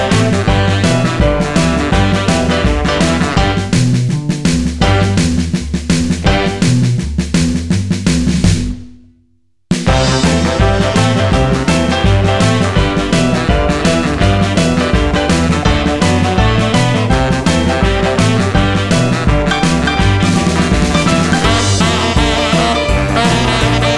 No Piano Rock 'n' Roll 2:12 Buy £1.50